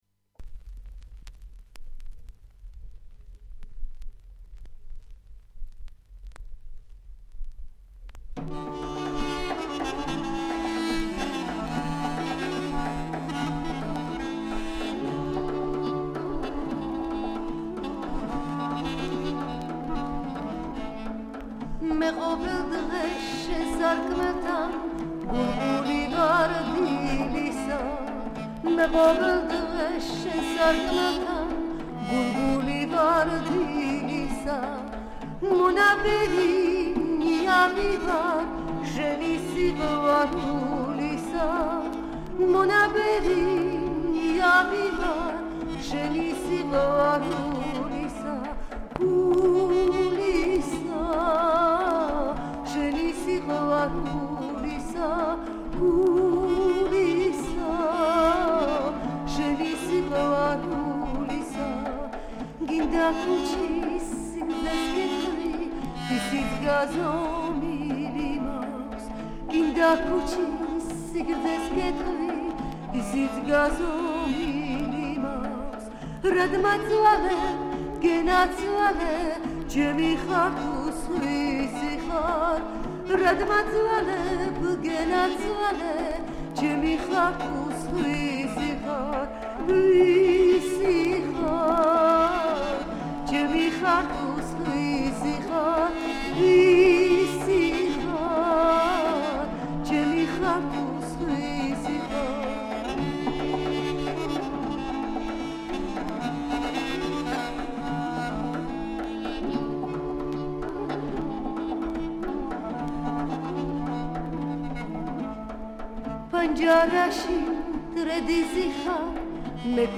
საკვანძო სიტყვები: ქართული ქალაქური სიმღერა
ქართული საესტრადო მუსიკა